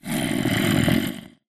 zombie1.ogg